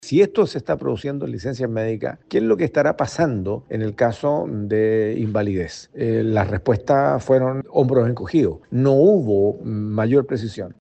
El senador Demócrata Cristiano, Iván Flores, quien preside la Comisión de Salud de la Cámara Alta, sigo que estos nuevos flancos muestran la falta de institucionalidad que Chile requiere.